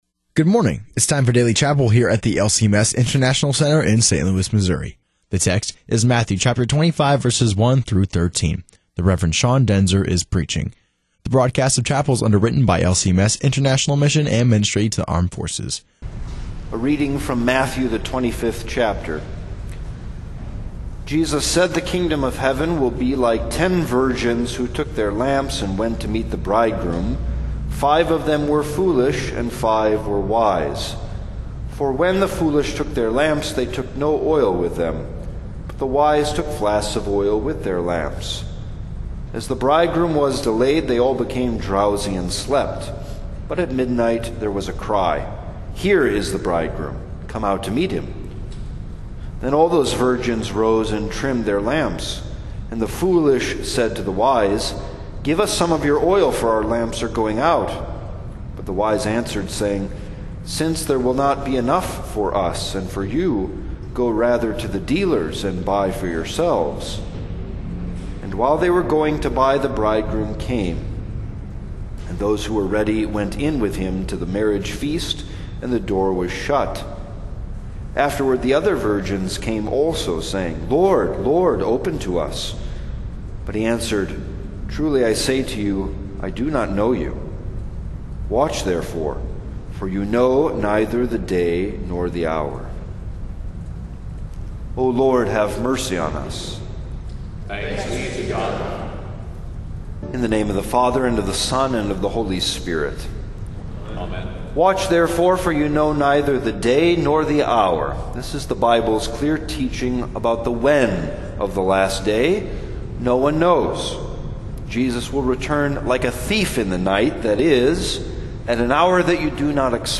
>> The broadcast of chapel services is brought to you by LCMS International Mission and Ministry to Armed Forces.